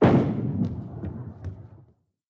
ball_hitting.mp3